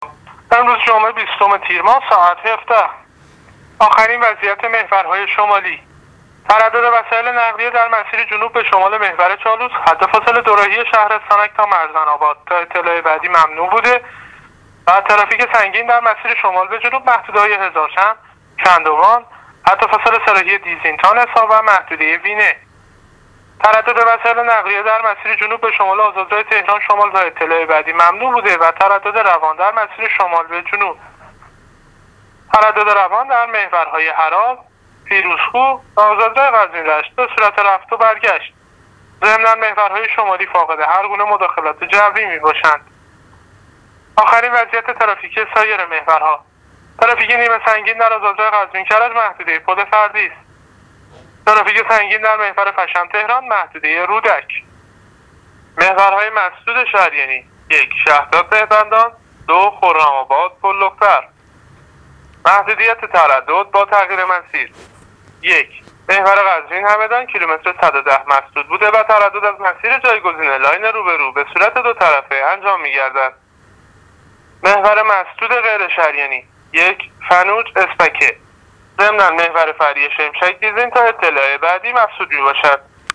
گزارش رادیواینترنتی از وضعیت ترافیکی جاده‌ها تا ساعت ۱۷ جمعه ۲۰ تیر